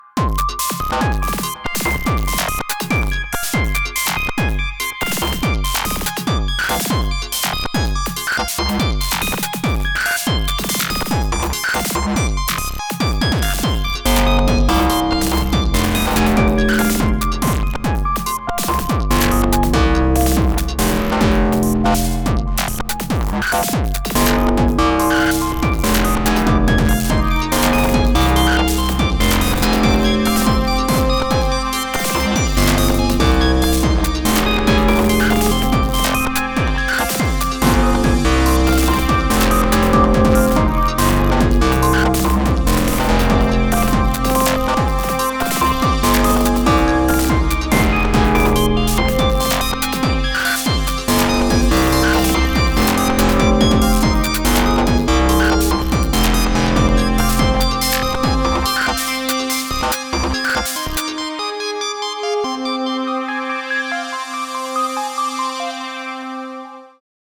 Swarm can do a lot!
the little motif that starts out: SWARM
strings (this time more synthetic sounding): SWARM
bass motif: RAW in ring mod mode
and analog kick and glitchy probabilty drums …